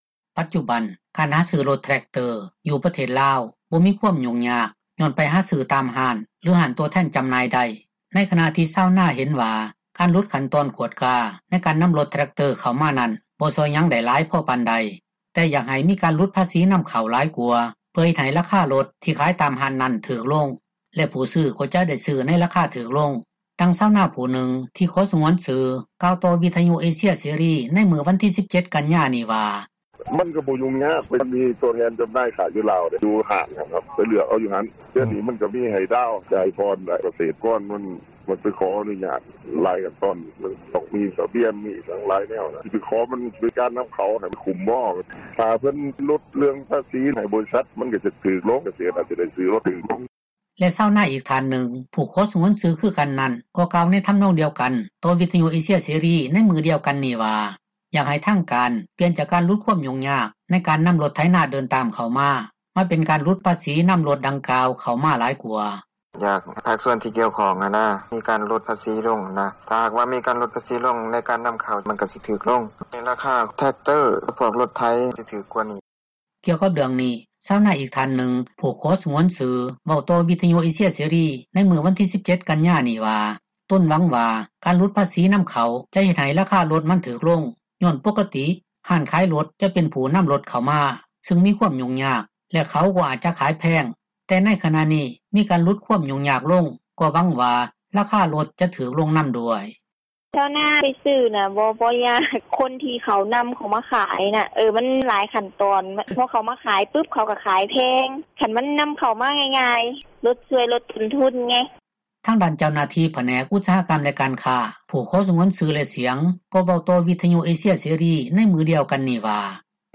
ດັ່ງຊາວນາຜູ້ນຶ່ງ ຜູ້ຊໍສງວນຊື່ທ່ານນຶ່ງກ່າວຕໍ່ ວິທຍຸເອເຊັຽເສຣີ ໃນມື້ວັນທີ 17 ກັນຍາ ນີ້ວ່າ: